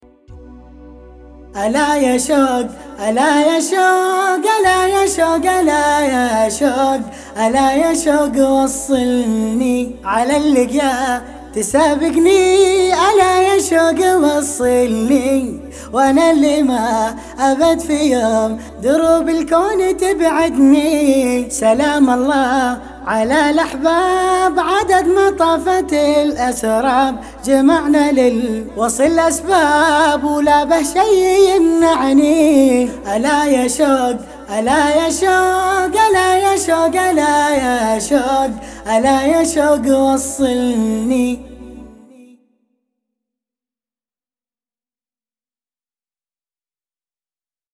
بيات